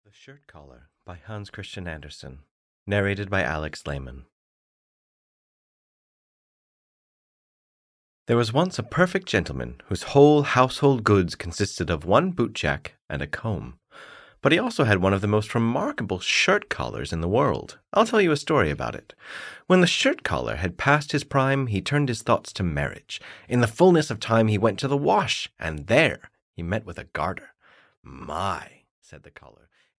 The Shirt Collar (EN) audiokniha
Ukázka z knihy